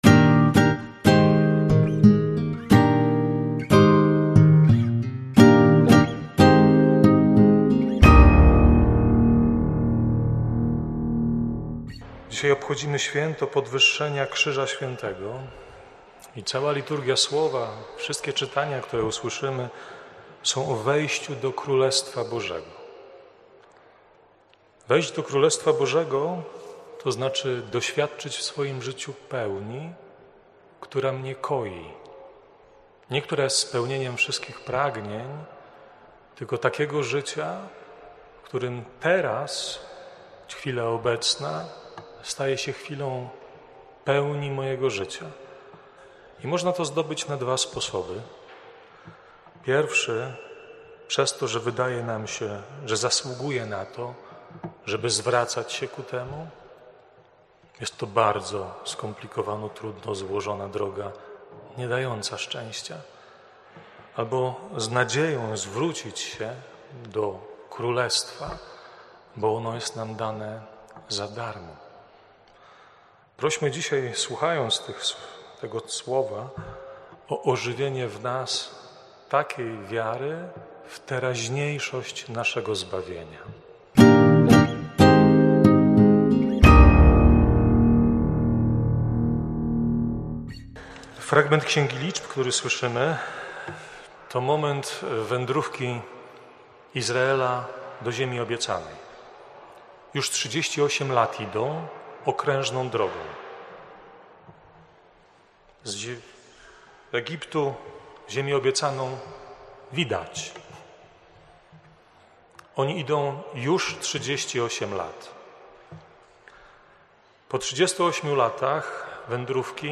kazania.